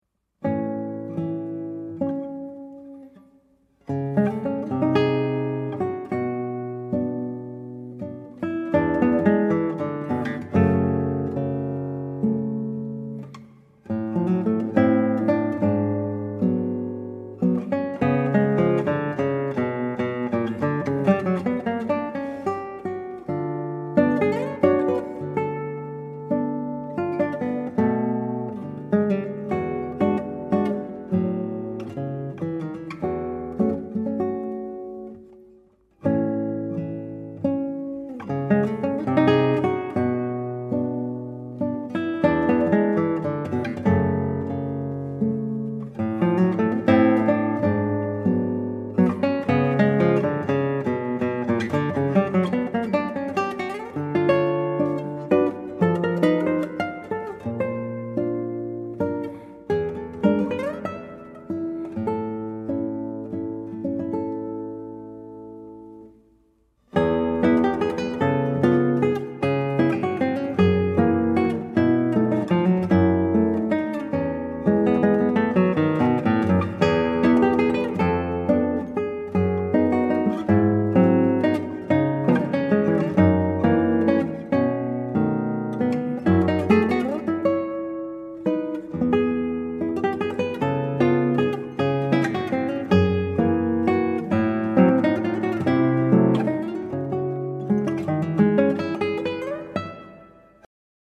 I present these arrangements within the same sensibility.